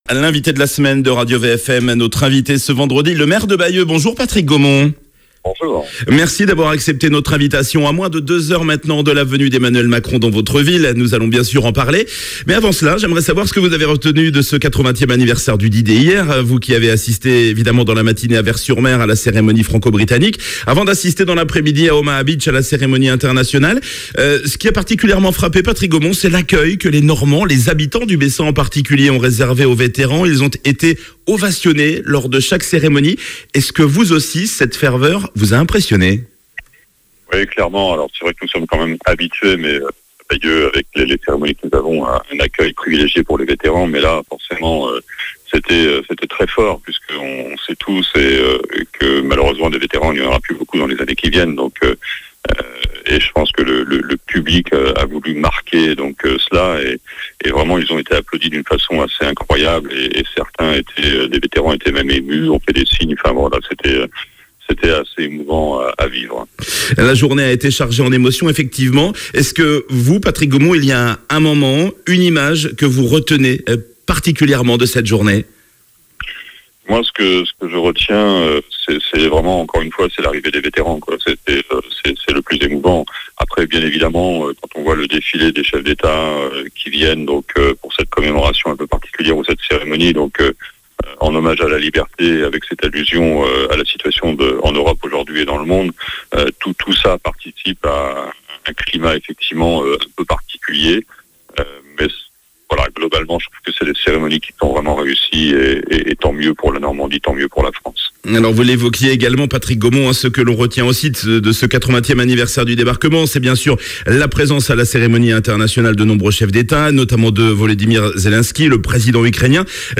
Patrick Gomont L'invité de la semaine sur RadioVFM, Le maire de Bayeux Patrick Gomont à l'occasion du 80ème anniversaire du débarquement !